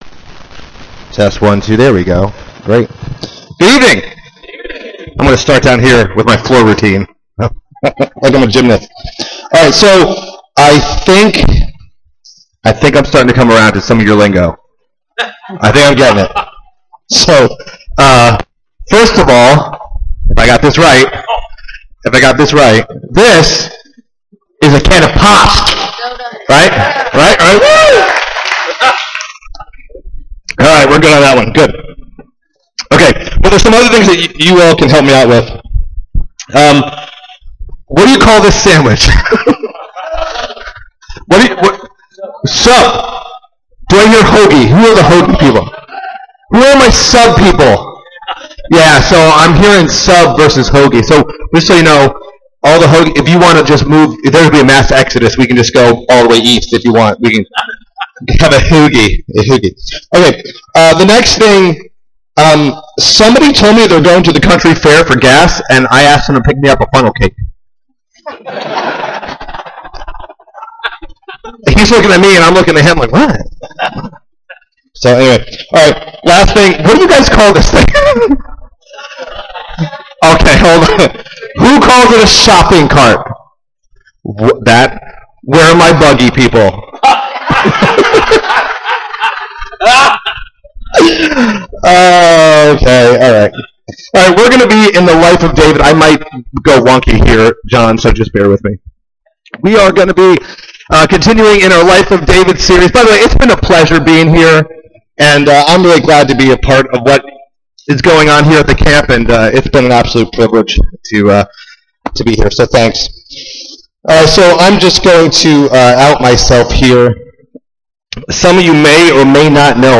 Family Camp 2023